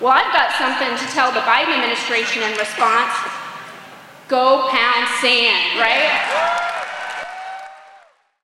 Iowa Attorney General Brenna Bird told state convention delegates the situation at the U-S/Mexico border out of control and she’ll defend the new state law in court.